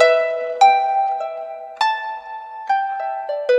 Harp08_100_G.wav